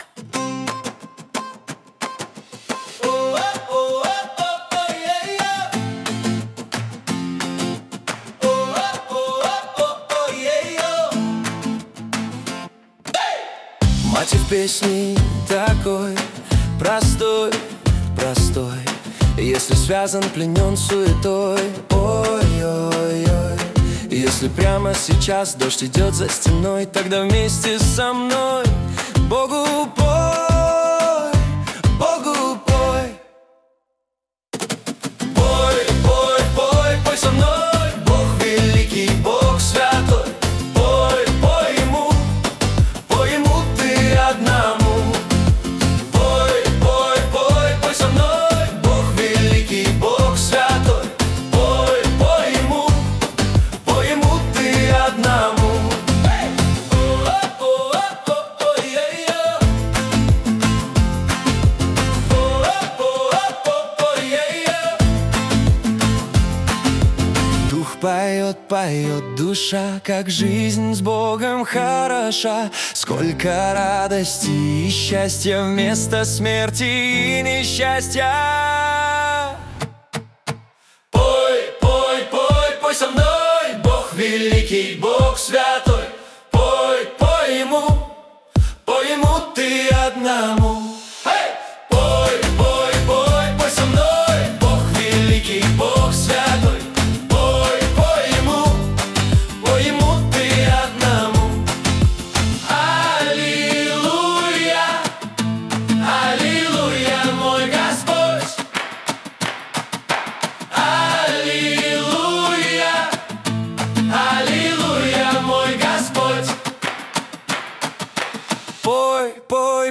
песня ai
390 просмотров 1038 прослушиваний 129 скачиваний BPM: 89